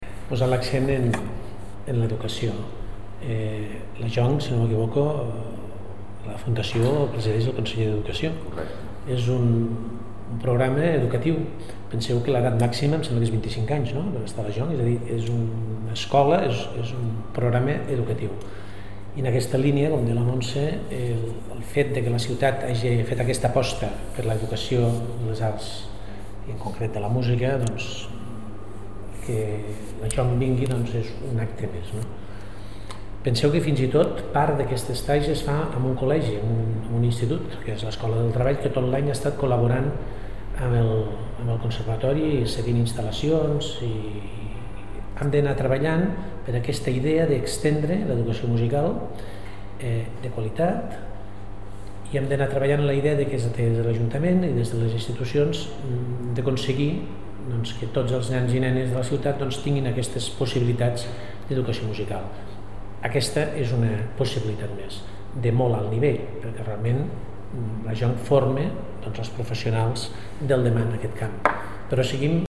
tall-de-veu-del-regidor-deducacio-jesus-castillo